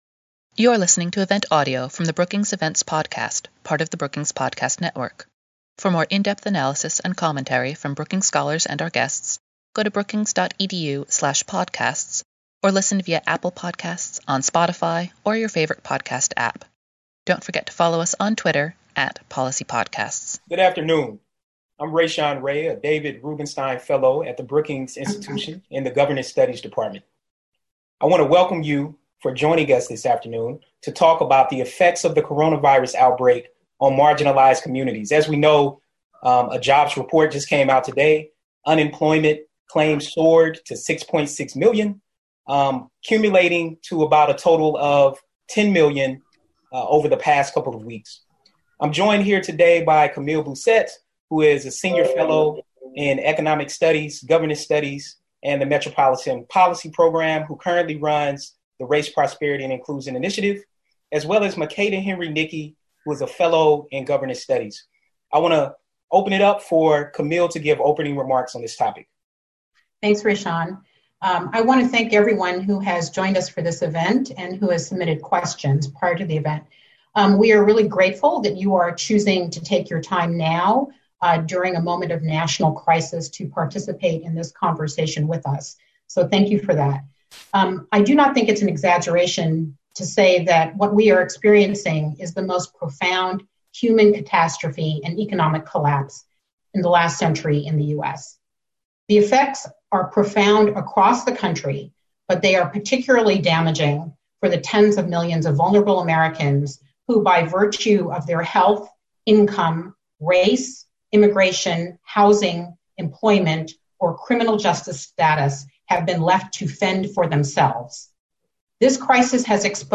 Webinar: The effects of the coronavirus outbreak on marginalized communities | Brookings
On Thursday, April 2, Brookings hosted a webinar discussion to address how the coronavirus pandemic is impacting low-income and vulnerable communities.